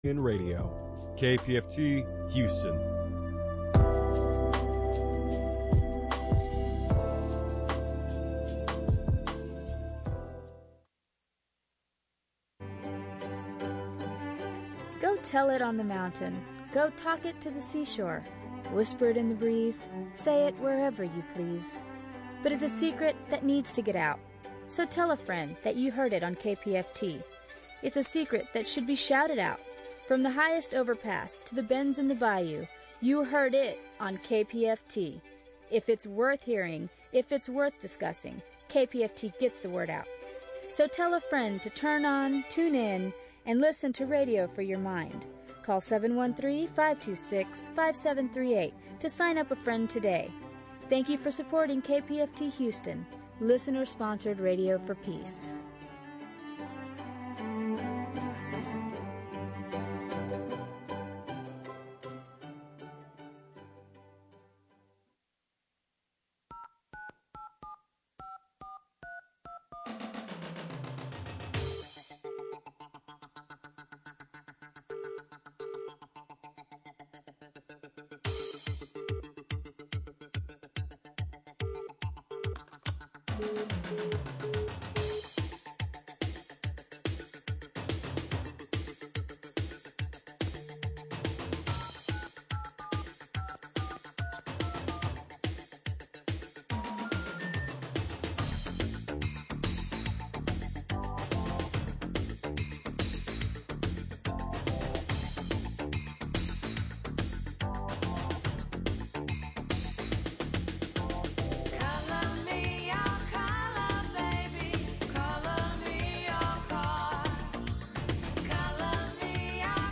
Listen live on the radio, or on the internet from anywhere in the world!